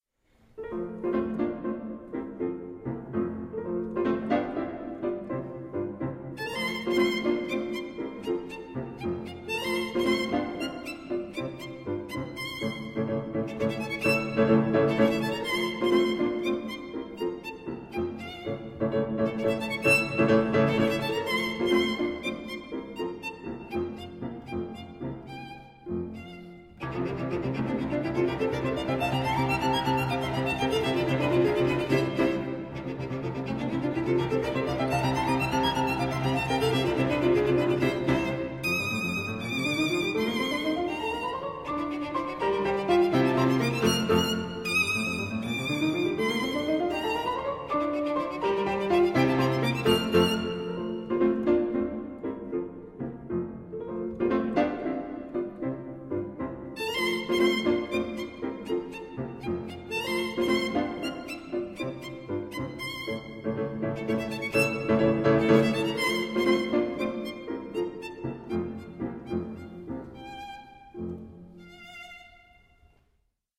violin
piano Live recording: La Fenice Theatre, Venice (Italy)